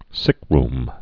(sĭkrm, -rm)